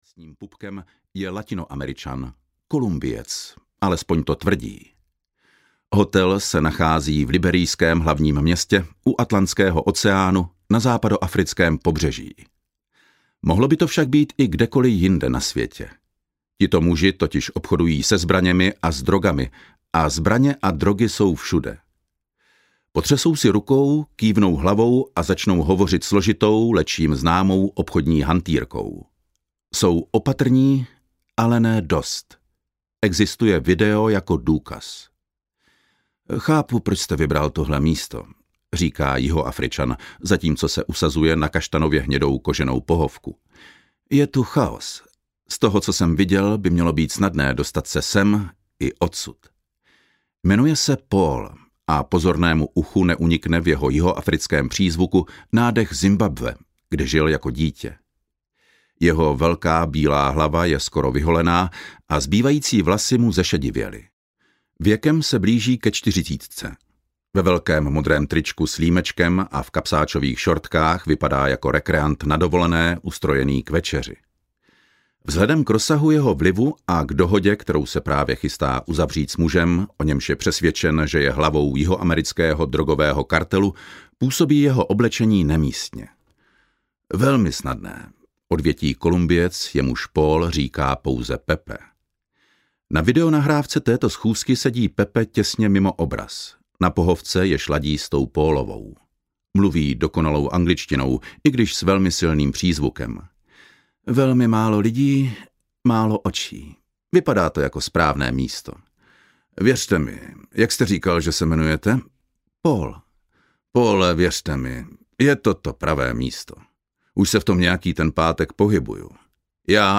Mastermind audiokniha
Ukázka z knihy